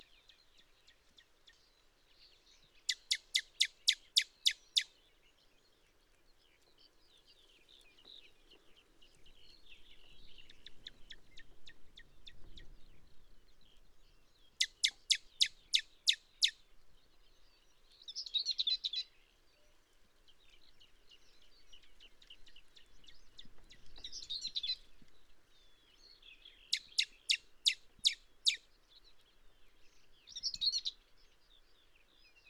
PFR06479, Steppe Pika, excitement calls
steppe lake near Shar, Kazakhstan